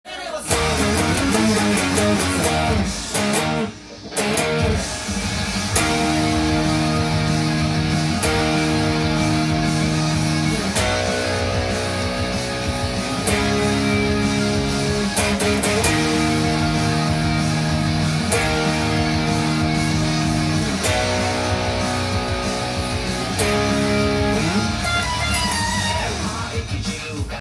音源にあわせて譜面通り弾いてみました
はかなりテンポが速いので
パワーコードでブルーノートスケールというブルージーな
スピード感のあるロックナンバーなので、初級から中級の
エレキギター練習曲にぴったりです。